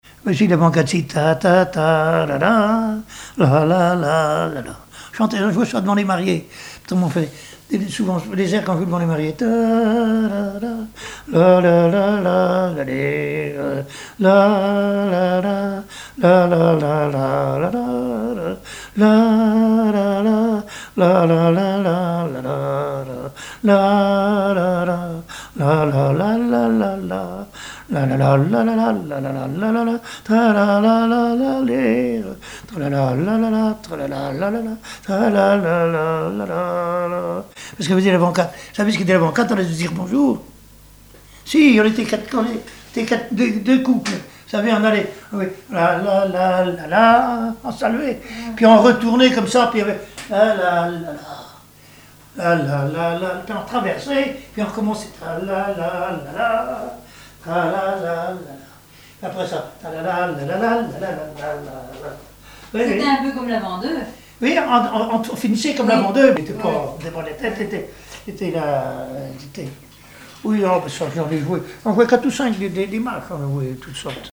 Joué devant les mariés
Pièce musicale inédite